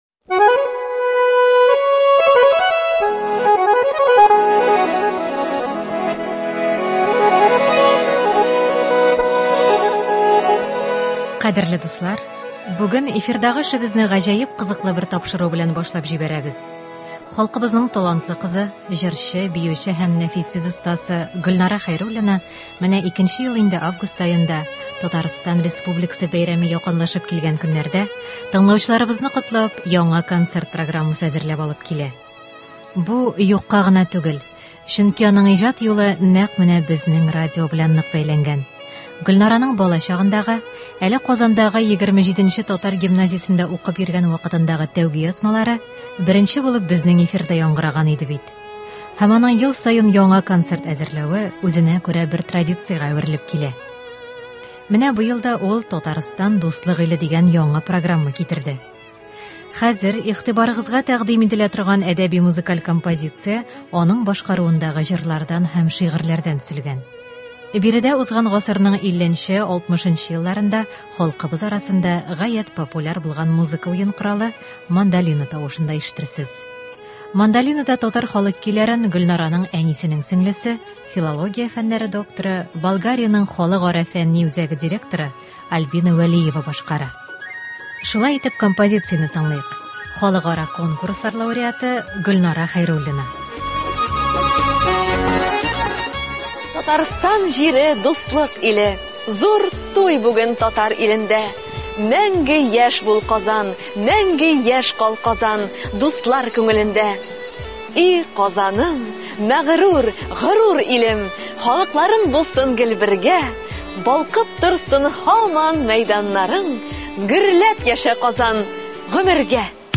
Әдәби-музыкаль композиция (14.08.21)